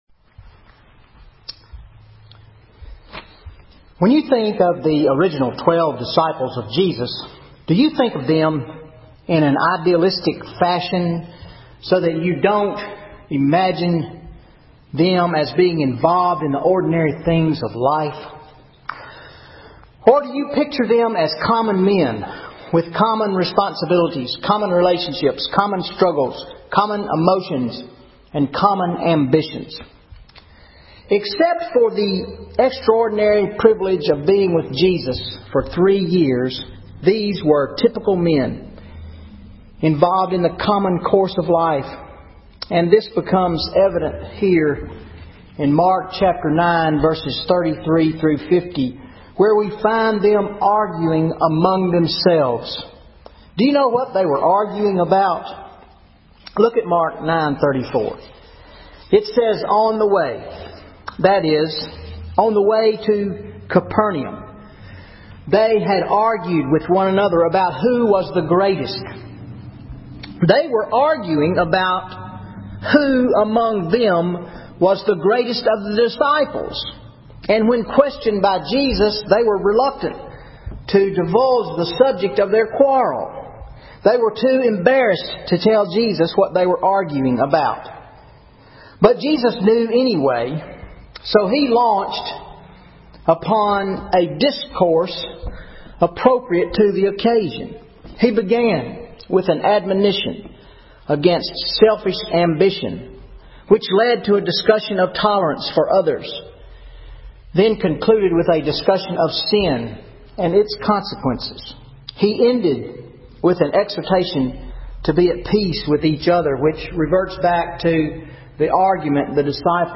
24 February 2013 Sermon Mark 9:30-50 Marks of Christian Discipleship